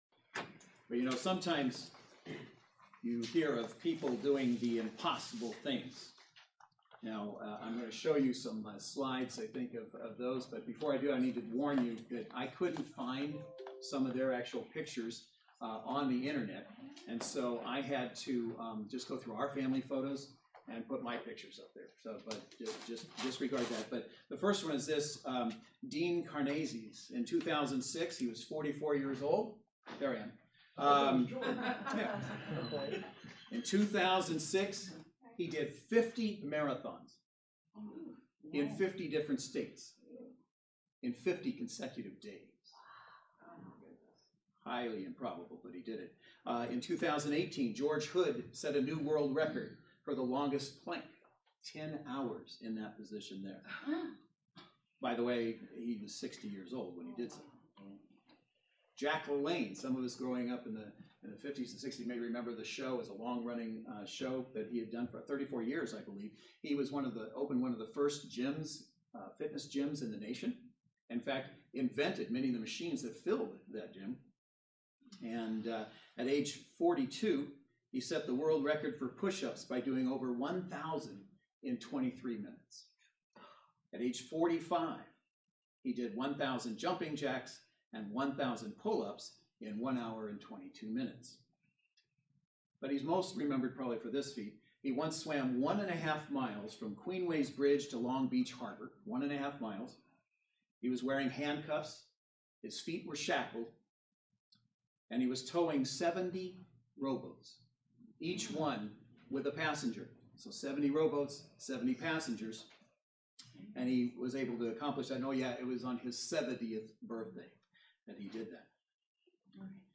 Mark 19:16-26 Service Type: Saturday Worship Service Bible Text